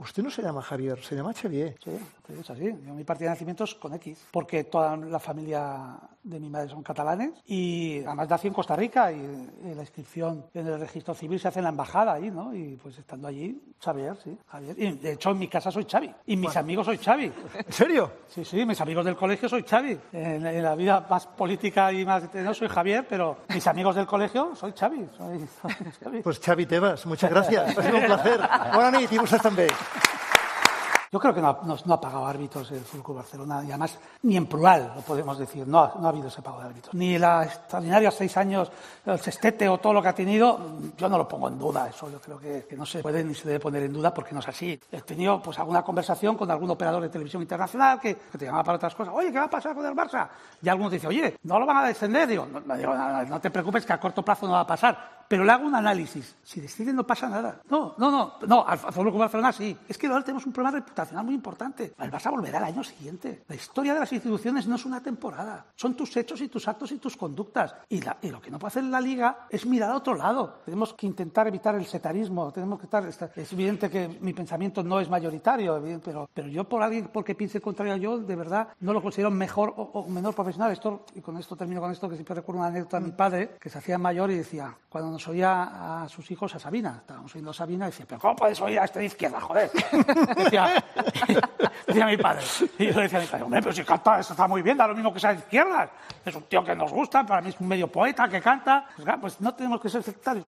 Tebas opina sobre el 'caso Negreira' en un acto organizado por el periódico 'La Vanguardia'